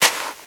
STEPS Sand, Walk 01.wav